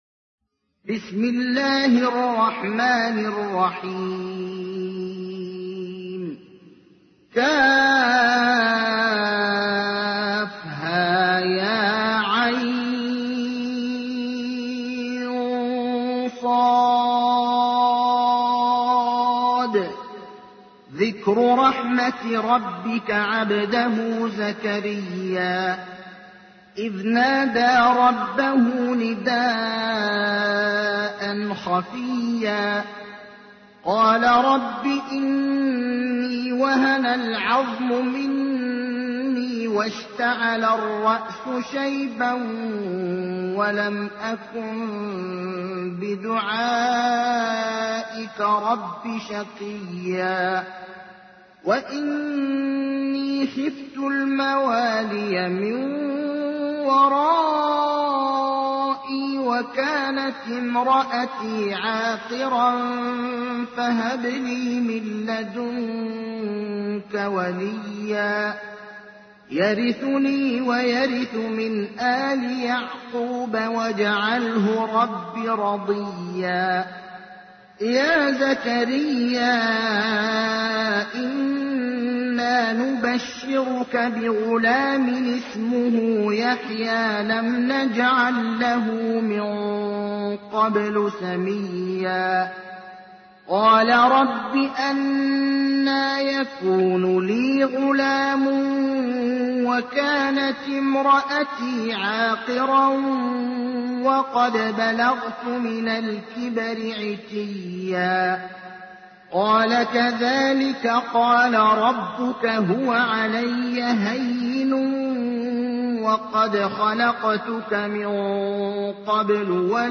تحميل : 19. سورة مريم / القارئ ابراهيم الأخضر / القرآن الكريم / موقع يا حسين